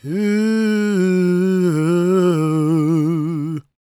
GOSPMALE030.wav